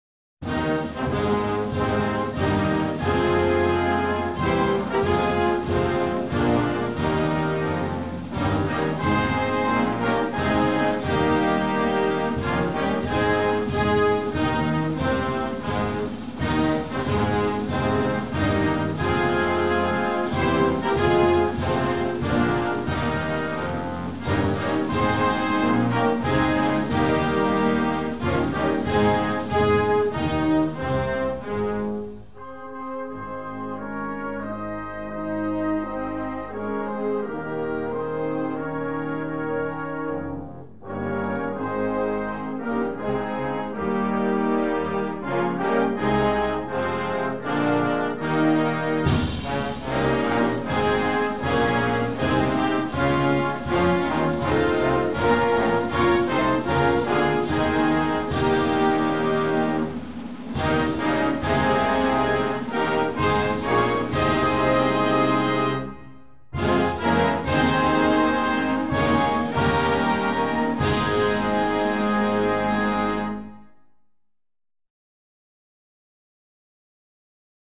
"The Star-Spangled Banner" performed by the United States Navy Band.flac